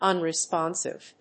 音節un・re・spon・sive 発音記号・読み方
/`ʌnrɪspάnsɪv(米国英語), ˌʌnri:ˈspɑ:nsɪv(英国英語)/